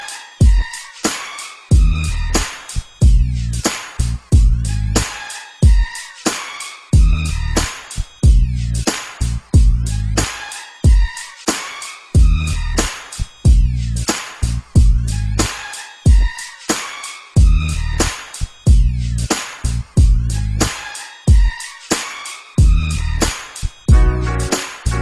Nada dering Coin falling sound
Message Tones
new cool , coin drop sms tone ,